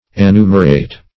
Search Result for " annumerate" : The Collaborative International Dictionary of English v.0.48: Annumerate \An*nu"mer*ate\, v. t. [L. annumeratus, p. p. of annumerare.